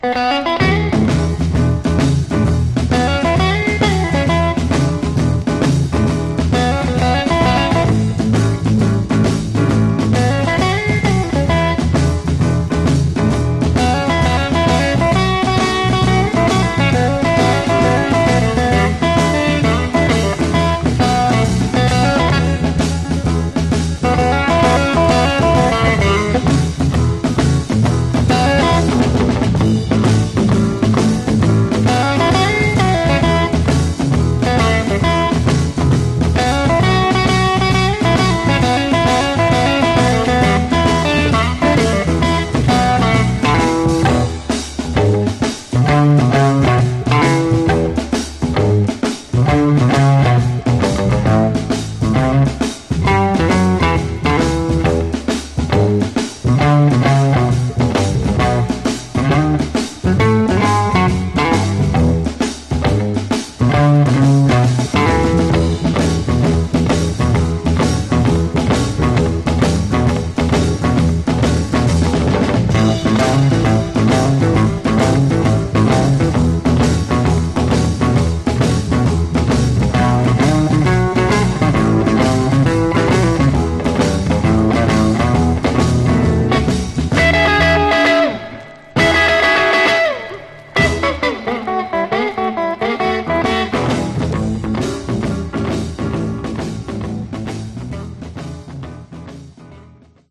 Genre: RnB Instrumentals